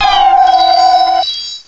Add all new cries
cry_not_cresselia.aif